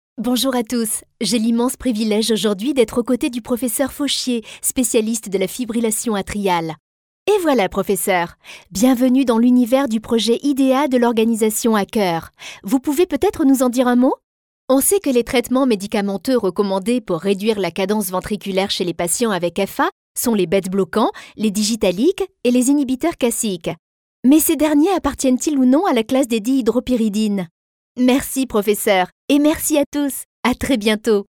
Voix jeune et fraiche mais pouvant être aussi plus mature ou plus sensuelle.
Sprechprobe: eLearning (Muttersprache):